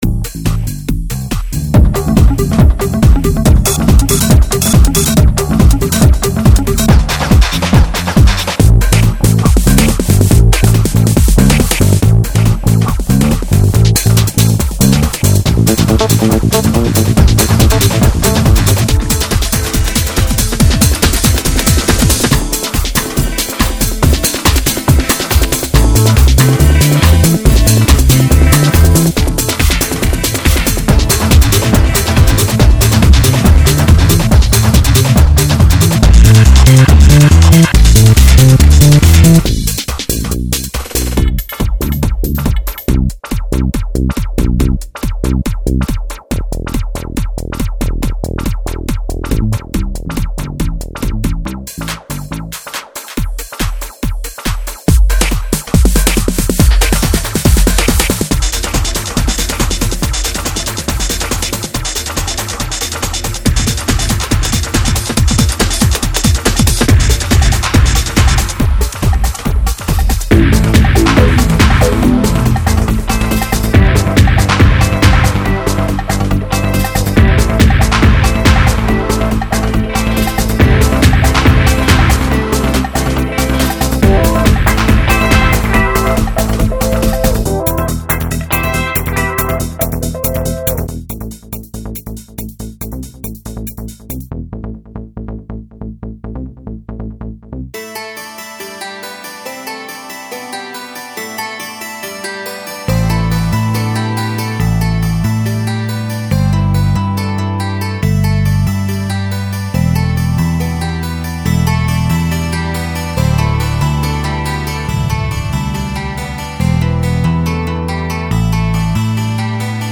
(Ambient and Classic to Action)